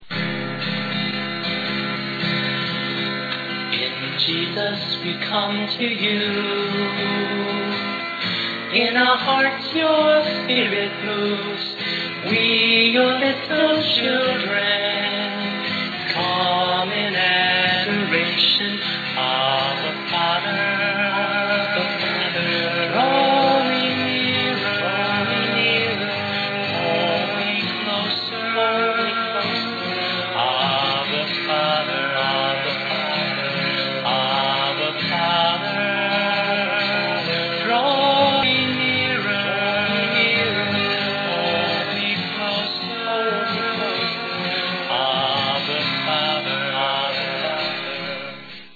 when you record in the back room